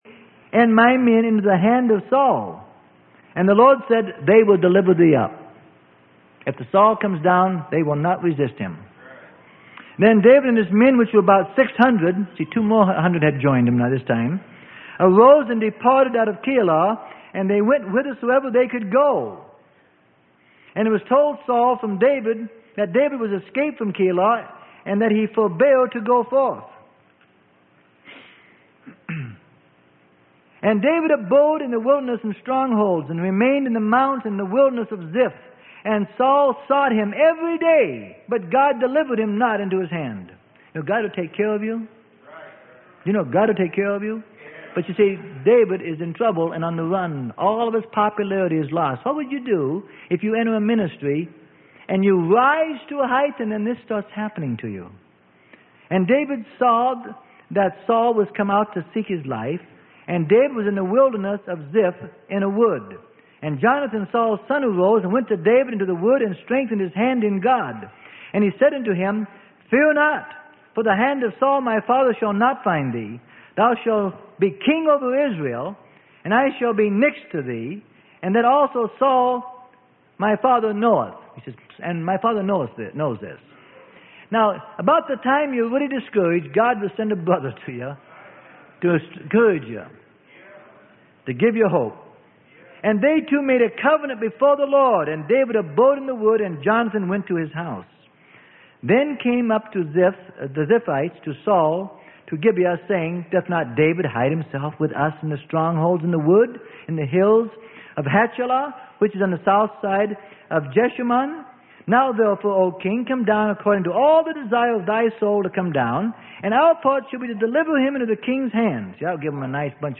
Sermon: THE MAKING OF A SAINT IN THE FURNACE.